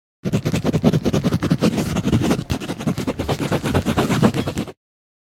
writing.ogg.mp3